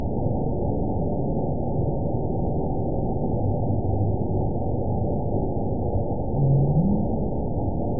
event 922866 date 04/29/25 time 05:32:16 GMT (2 days, 13 hours ago) score 9.15 location TSS-AB02 detected by nrw target species NRW annotations +NRW Spectrogram: Frequency (kHz) vs. Time (s) audio not available .wav